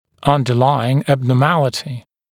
[ˌʌndə’laɪŋ ˌæbnɔː’mælɪtɪ][ˌандэ’лаин ˌэбно:’мэлити]лежащая в основе патология